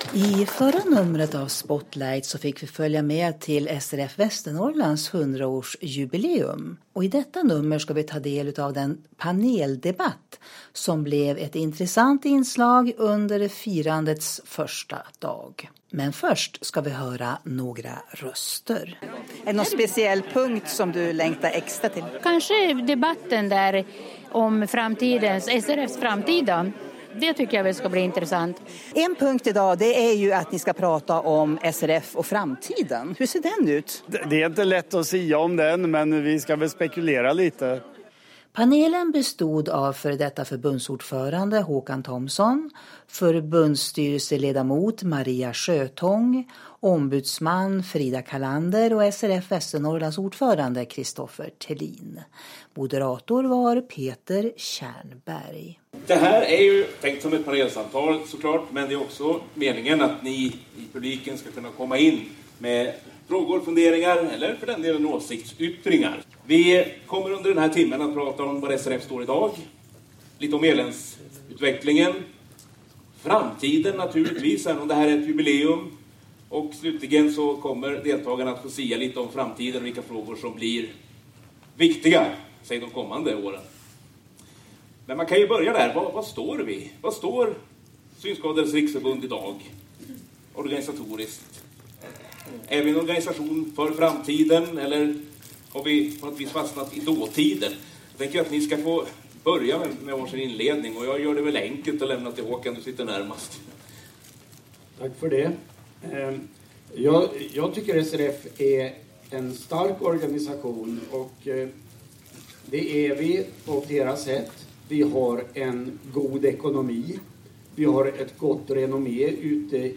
Under panelsamtalet vid SRF Västernorrland 100 års jubileum dryftades många frågor som nutid och framtid.